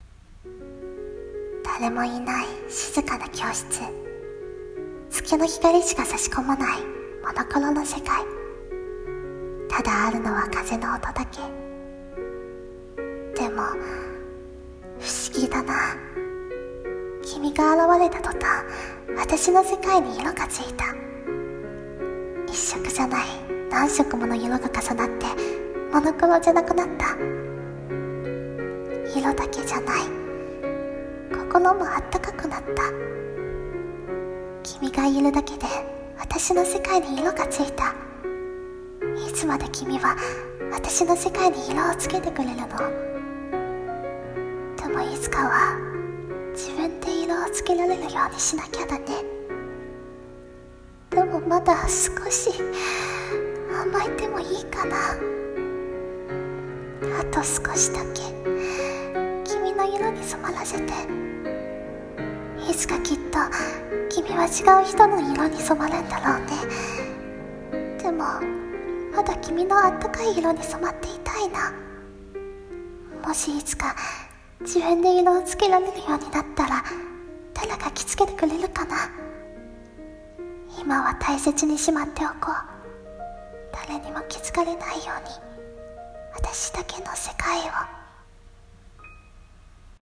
私の世界 声劇